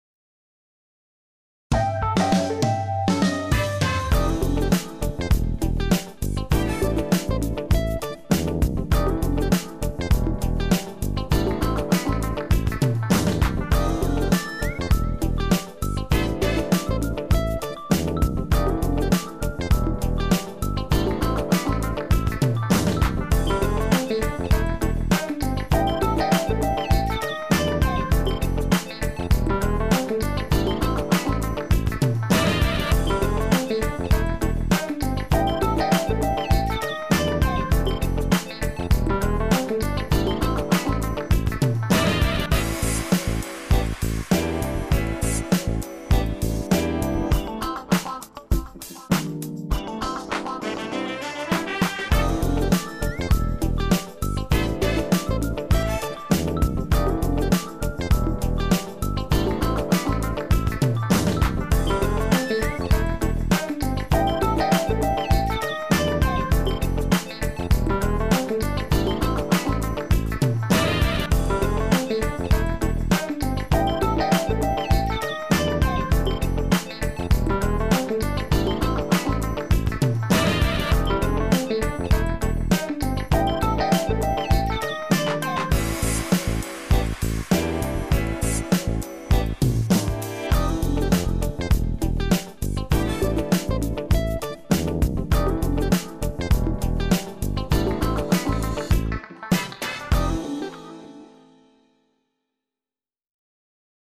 Son de la basse.
Le son est bien plein, avec énormément de velouté, j'adore :humm: .
C'est très typé Fender Jazz Bass, mais, soit c'est une boucle d'une phrase de basse samplée, soit c'est du VST, ça sonne trop régulier.
Tout le morceau sonne hyper synthétique, je penche pour une basse synthé.
Ca sonne à mi chemin entre le jeu aux doigts classique et une sorte de slap super mou (ou joué dans un ampli à lampes bien vieux).
Basse.Mp3